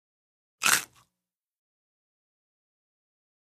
EatCrispySnackSngl PE678004
DINING - KITCHENS & EATING CRISPY SNACK: INT: Single crunches, bright.